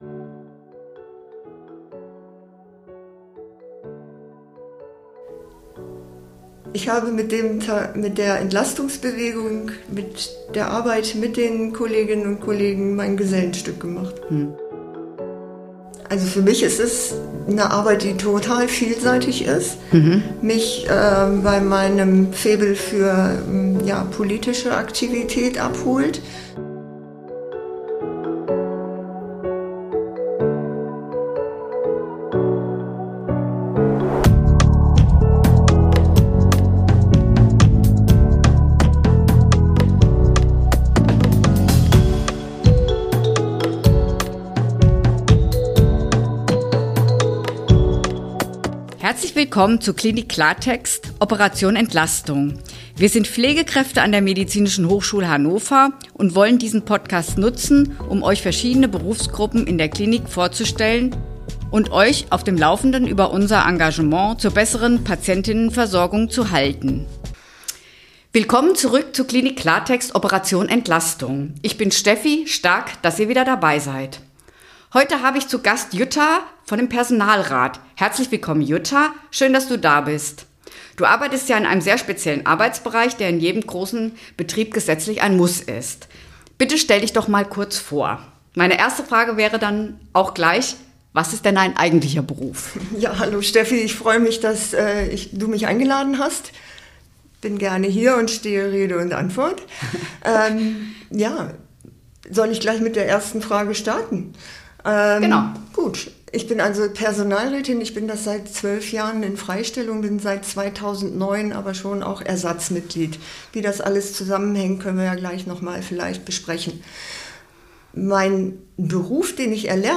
Personalrat – Ein Interview